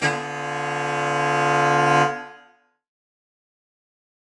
UC_HornSwellAlt_Ddim.wav